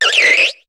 Cri de Ceribou dans Pokémon HOME.